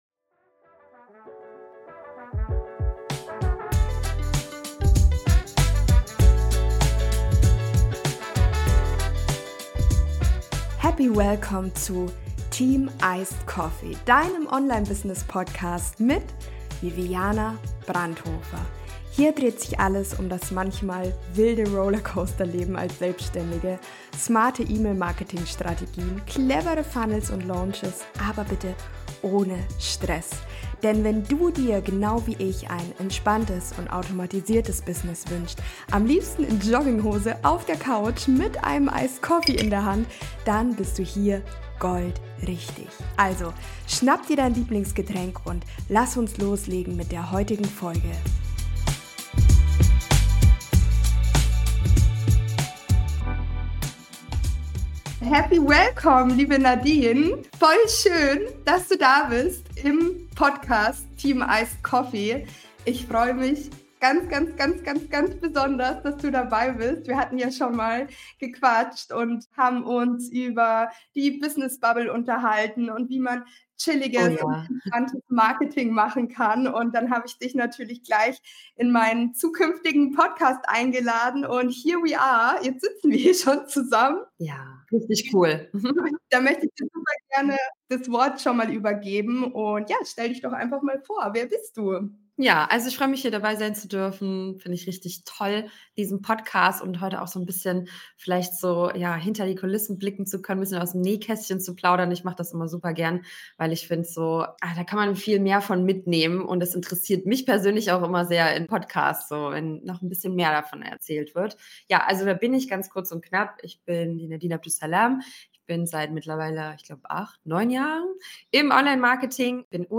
Erfolgreich Launchen: Interview